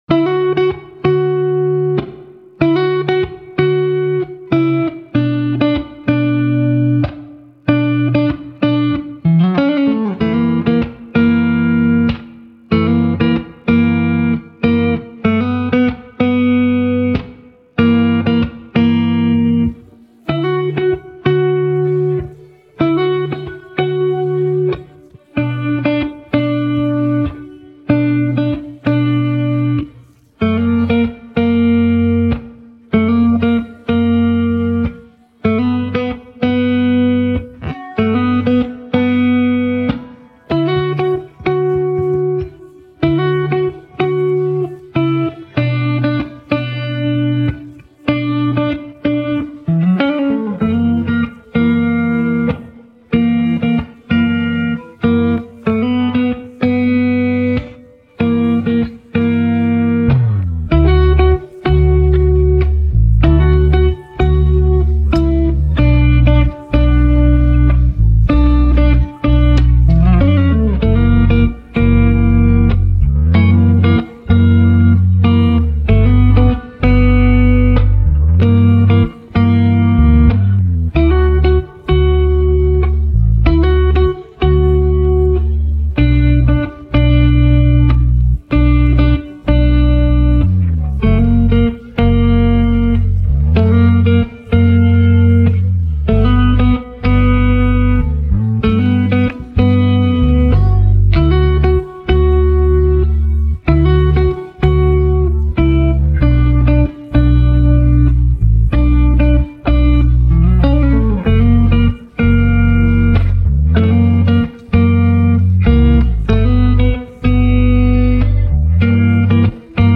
official instrumental
2026 in K-Pop Instrumentals